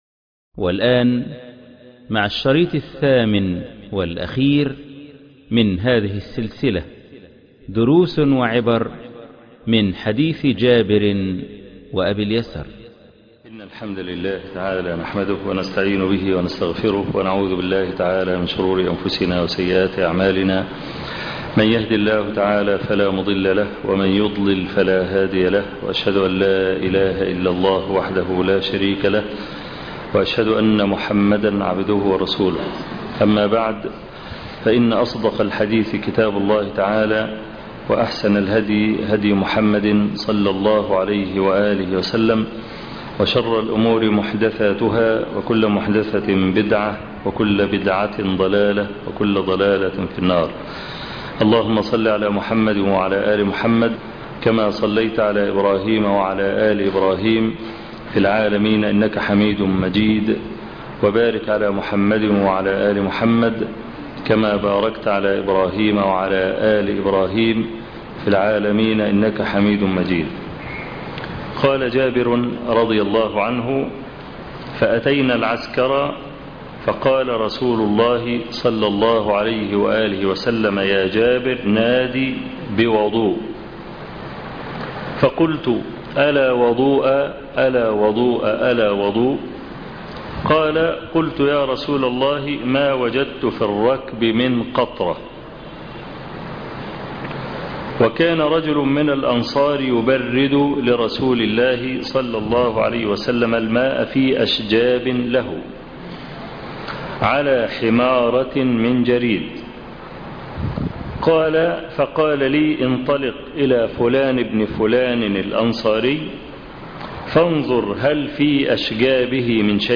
الدرس ( 8) دروس وعبر من حديث جابر وأبي اليسر - الشيخ أبو إسحاق الحويني